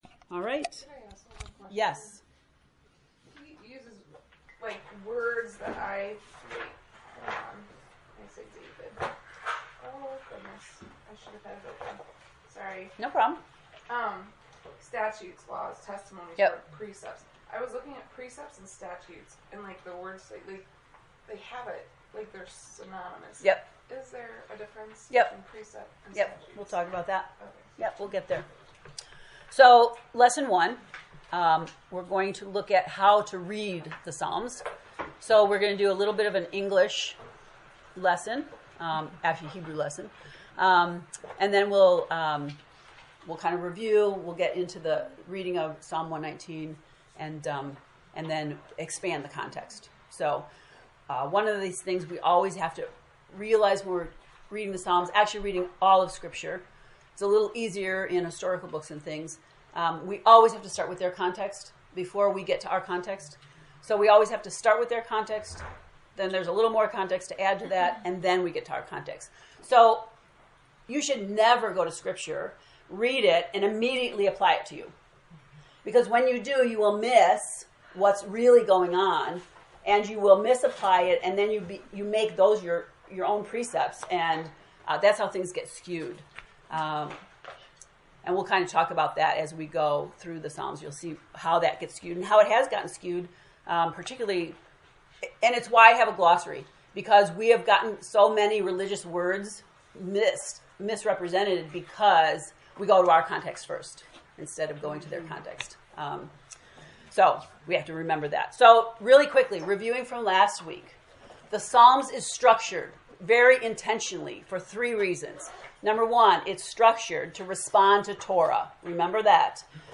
Click below for the lecture 1 “Reading the Psalms”: